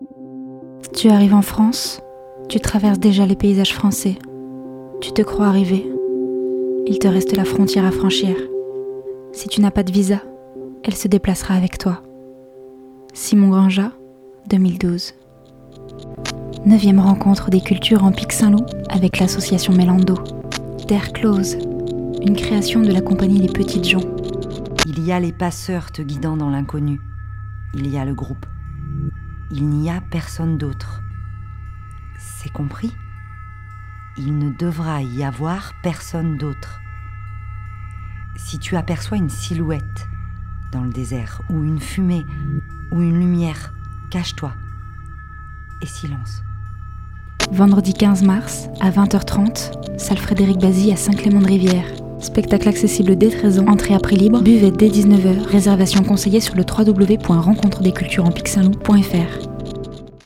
Bande annonce sonore réalisé par Radio Escapades >